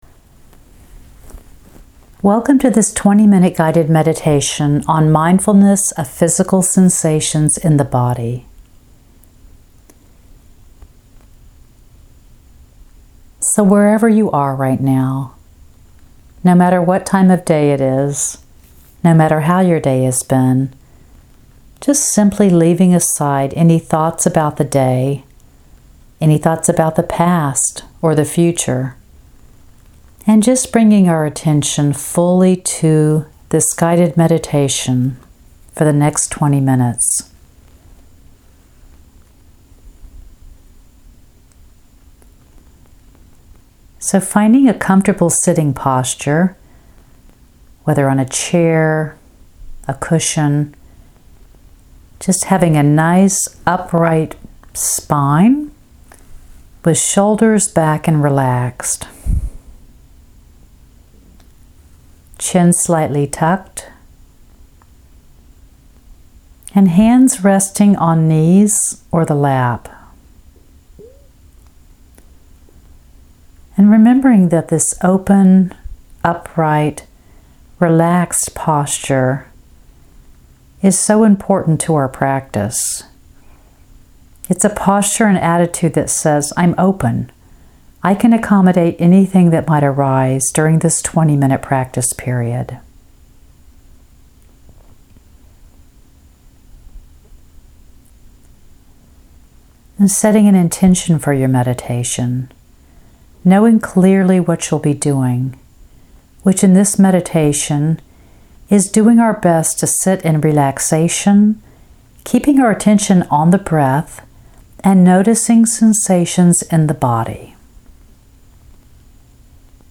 20 Min Guided Meditation.MP3